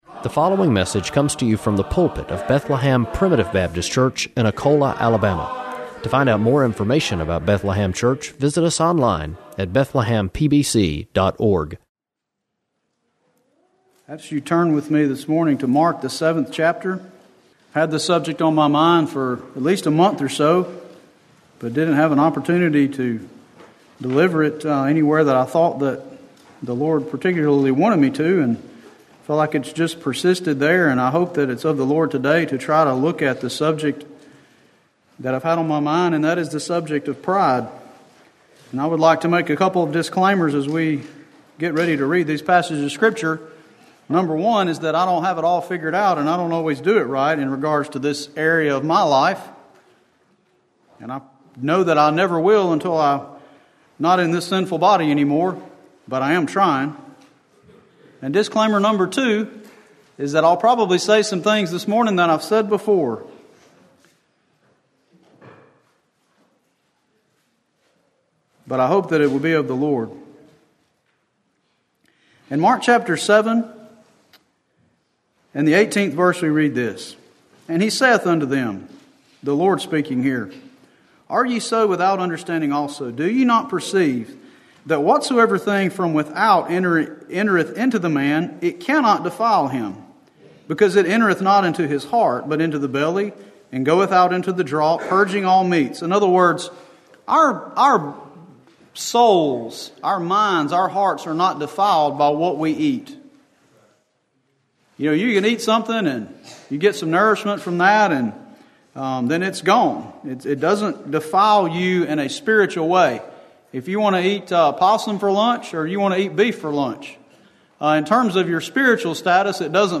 Preached February 11